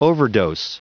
Prononciation du mot overdose en anglais (fichier audio)
Prononciation du mot : overdose